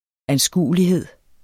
Udtale [ anˈsguˀəliˌheðˀ ]